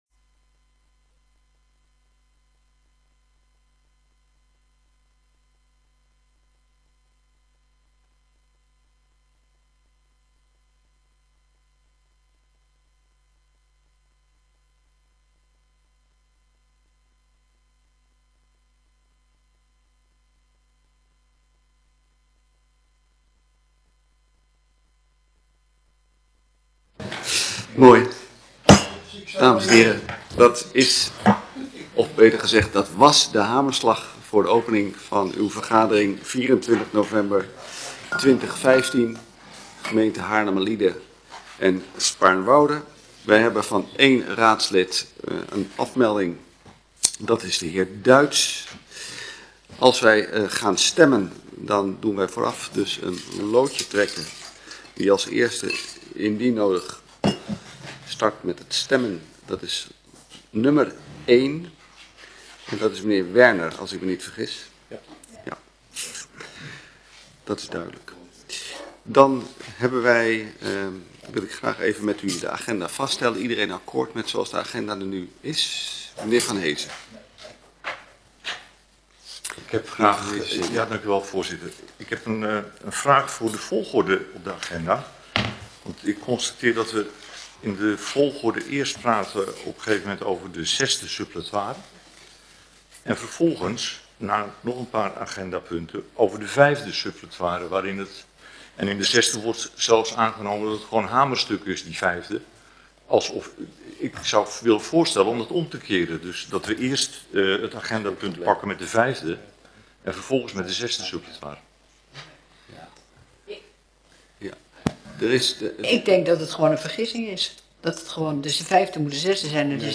Gemeenteraad 24 november 2015 20:00:00, Gemeente Haarlemmermliede
Download de volledige audio van deze vergadering